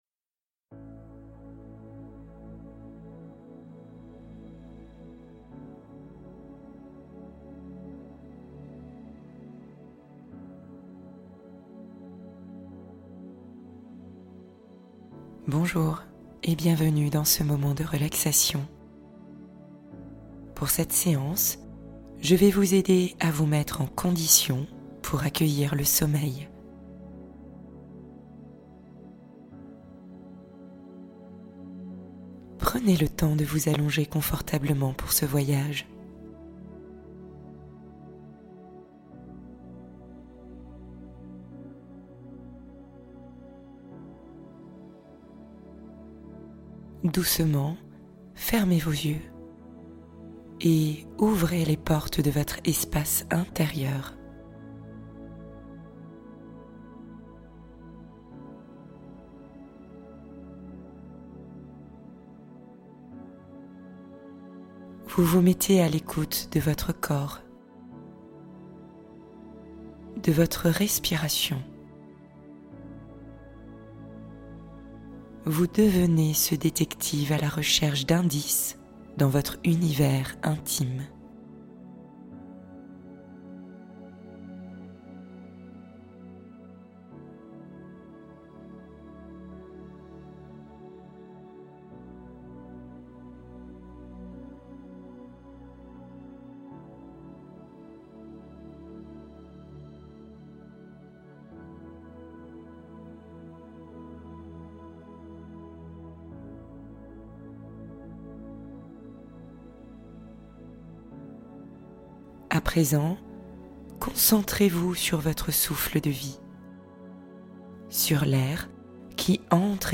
Préparez votre corps et votre esprit au sommeil parfait | Méditation rituel du soir